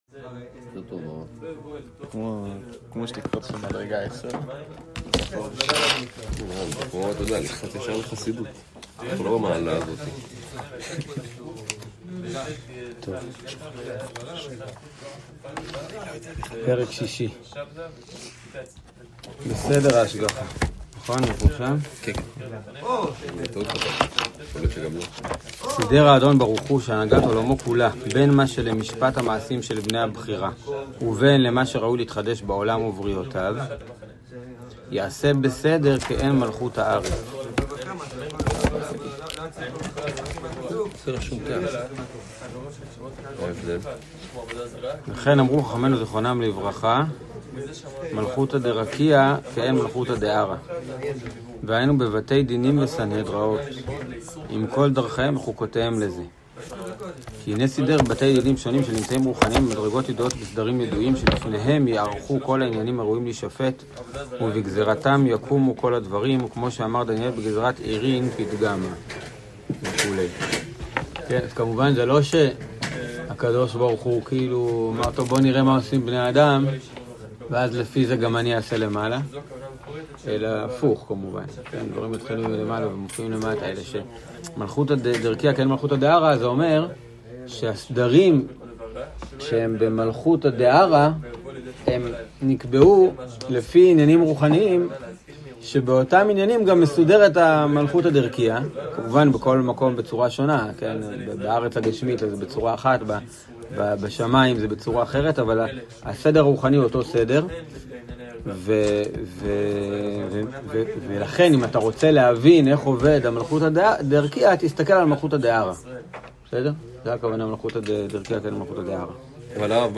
שיעור חלק ב'